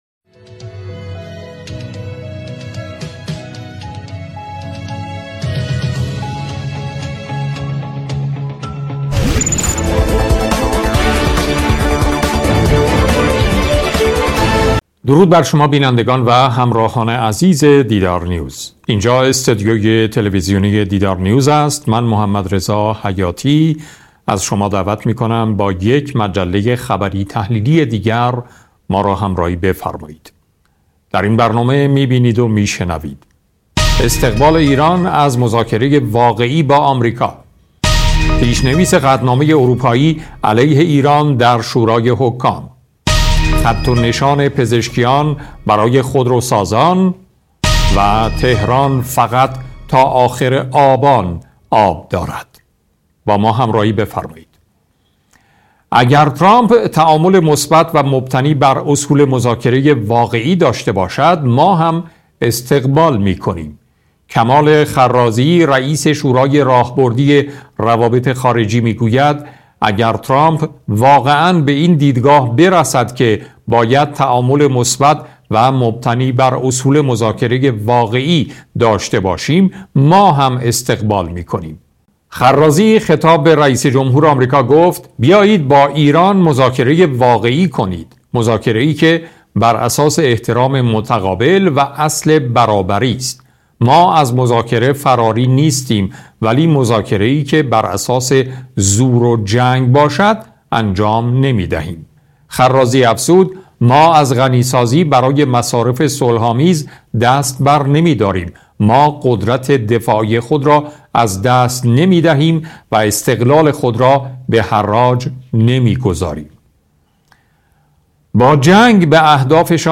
صدای مجله خبری تحلیلی دیدارنیوز با اجرای محمدرضا حیاتی و با حضور کارشناسان و صاحب نظران را می‌توانید اینجا گوش دهید.